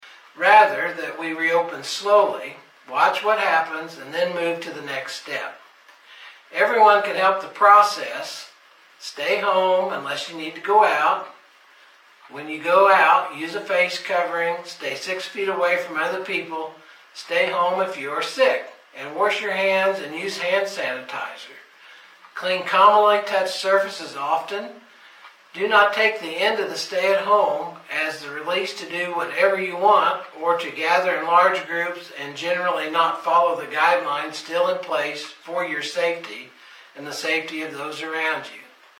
Pettis County Presiding Commissioner David Dick issued a message to update residents on the COVID-19 situation.